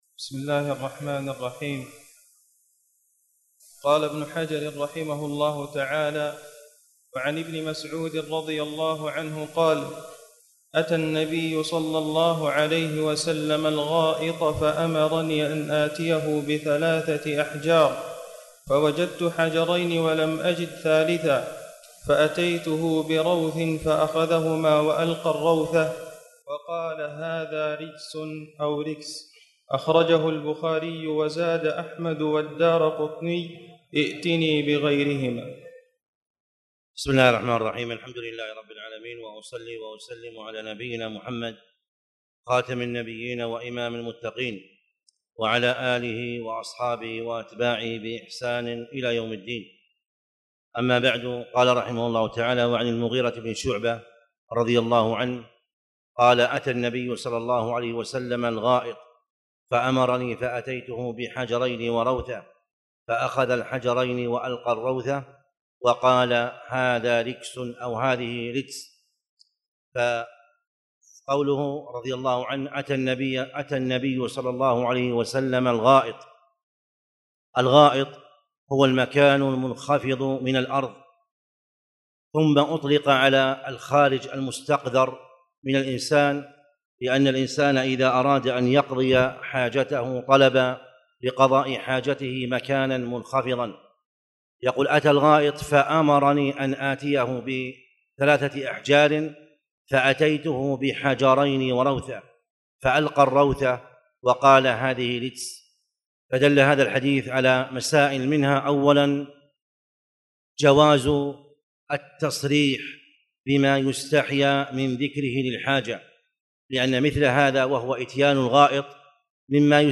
تاريخ النشر ٢١ ربيع الثاني ١٤٣٨ هـ المكان: المسجد الحرام الشيخ